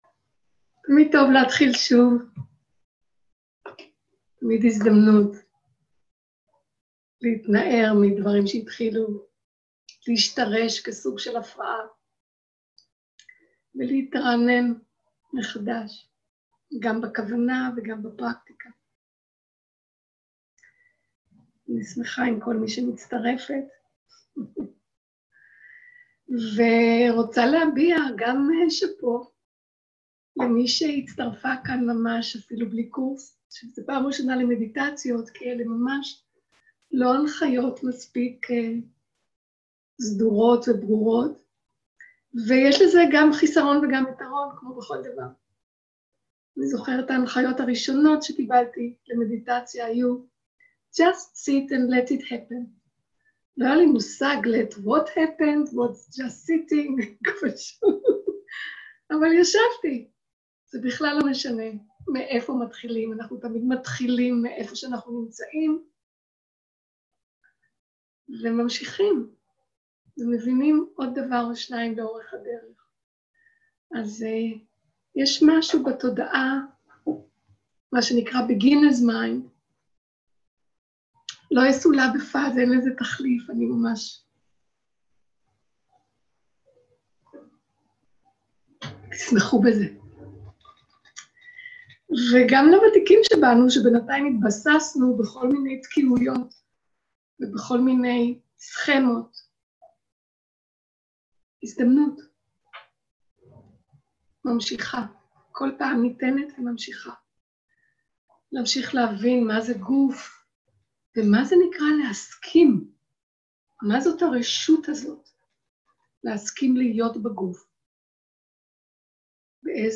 סוג ההקלטה: שיחת הנחיות למדיטציה
עברית איכות ההקלטה: איכות גבוהה מידע נוסף אודות ההקלטה